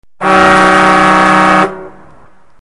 Efectos de sonido
BOCINA DE BOMBEROS
bocina_de_bomberos.mp3